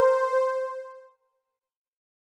Rusty Synth.wav